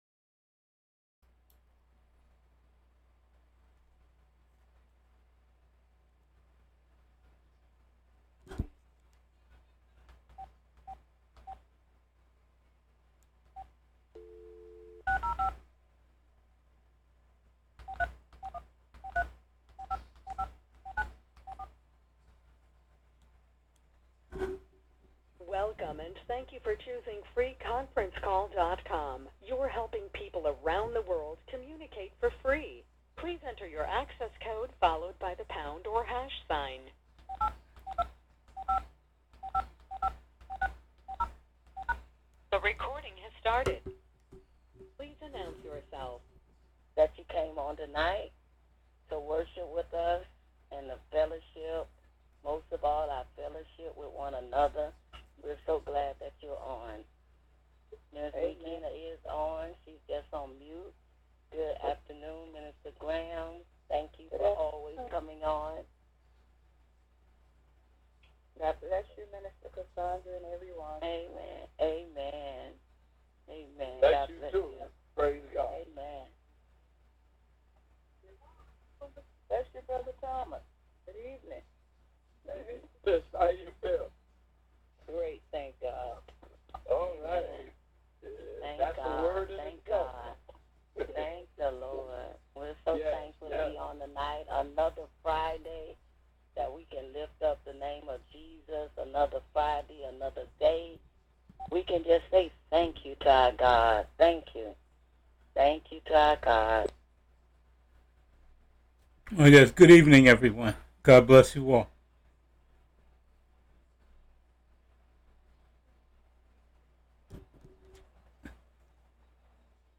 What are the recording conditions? A Bible study with the Meeting of the Saints.